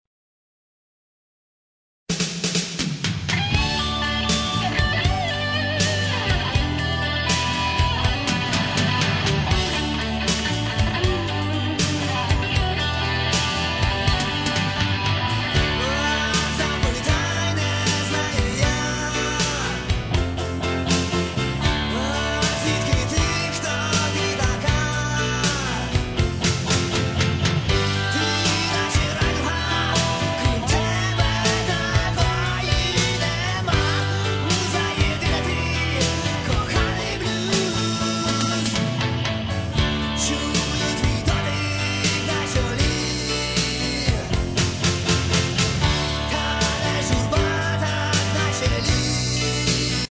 Rock (320)